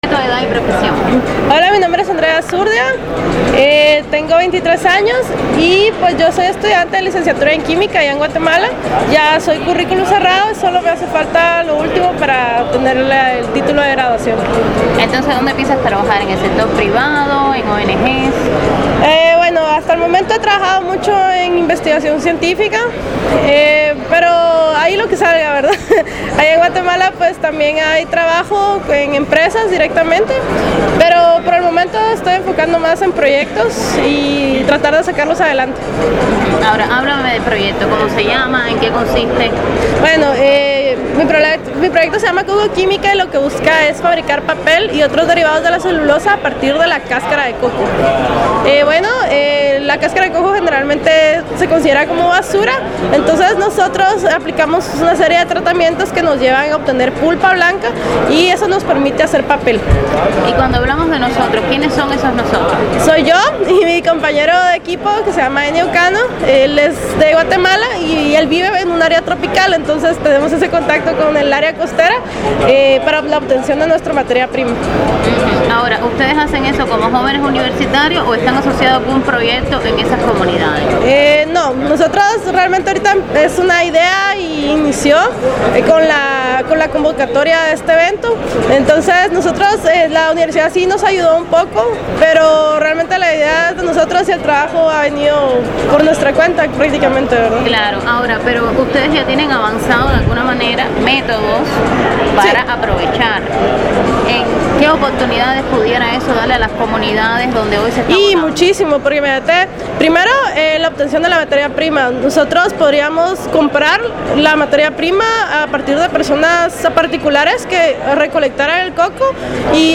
Entrevista-a-joven-guatemalteca1.mp3